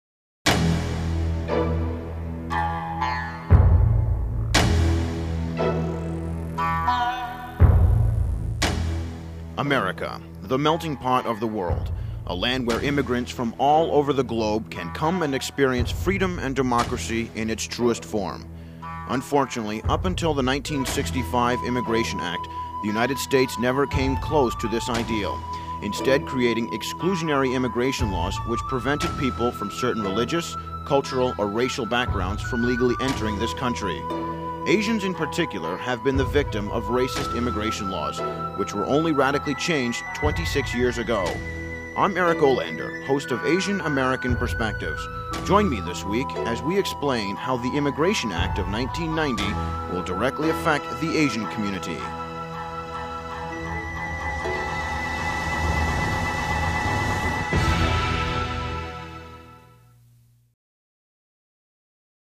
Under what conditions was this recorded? Each show was accompanied by a 30 second promotional spot that NPR affiliates could run during the week to invite listeners to tune in to Asian American Perspectives.